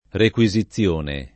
[ rek U i @ i ZZL1 ne ]